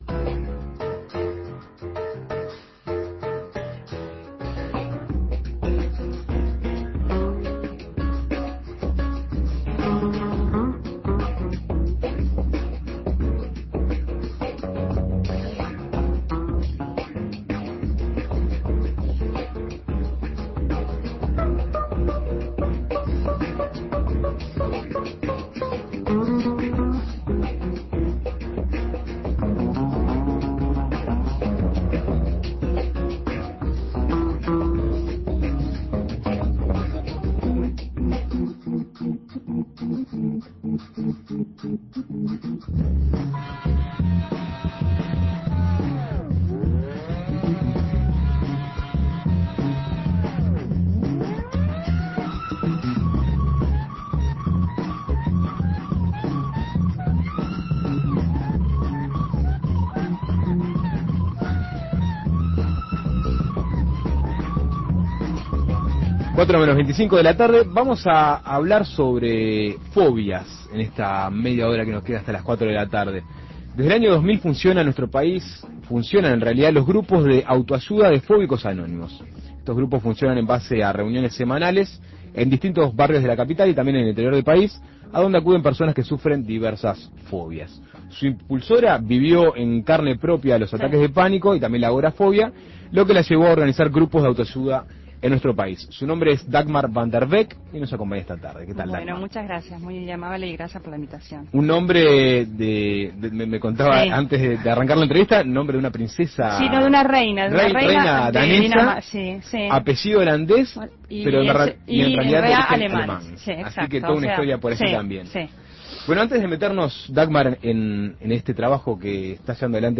A su vez, habló sobre el funcionamiento y estructura de los mismos. Escuche la entrevista.